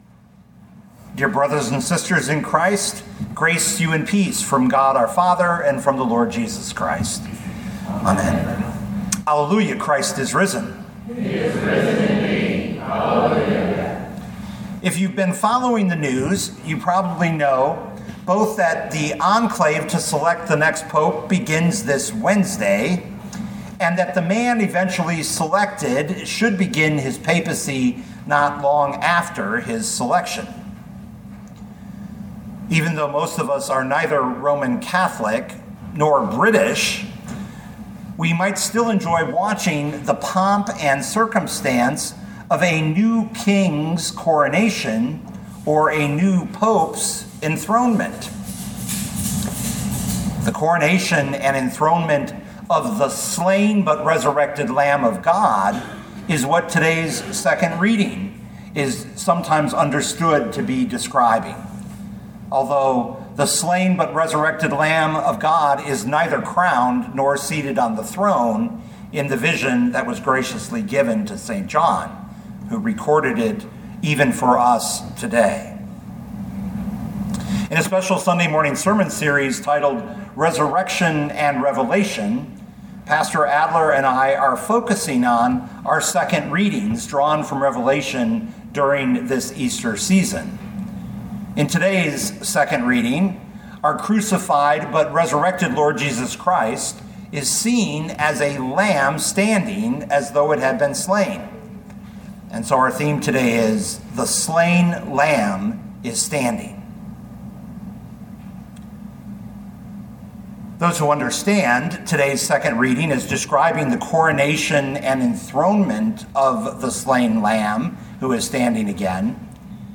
2025 Revelation 5:1-14 Listen to the sermon with the player below, or, download the audio.